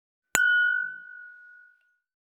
291シャンパングラス,ワイングラス乾杯,イタリアン,バル,フレンチ,夜景の見えるレストラン,チーン,カラン,キン,コーン,チリリン,カチン,チャリーン,クラン,
コップ